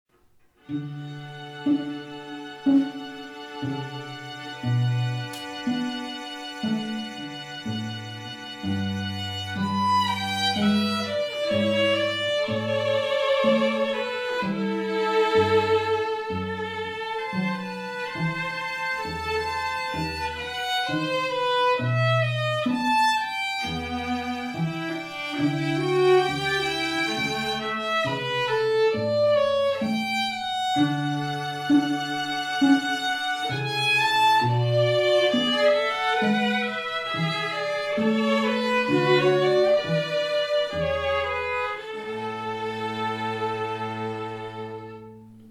STRING TRIO SAMPLES
FS_trio_-_Bach_Air.wma